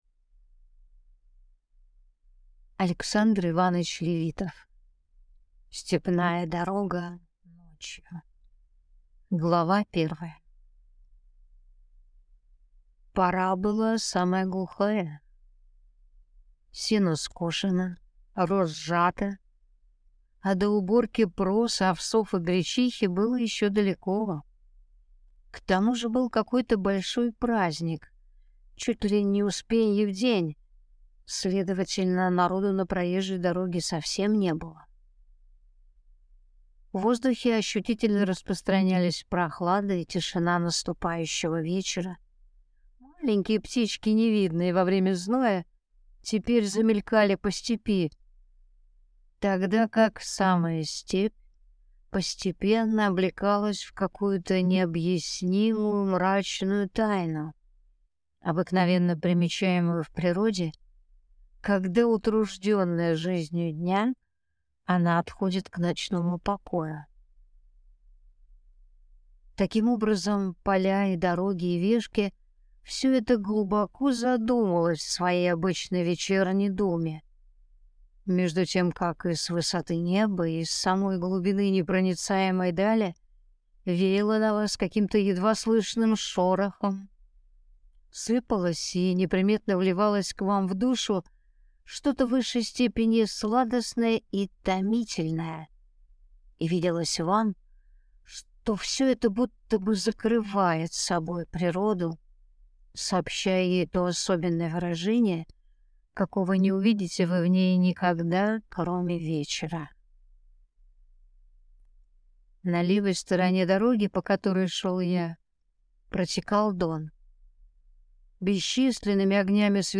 Аудиокнига Степная дорога ночью | Библиотека аудиокниг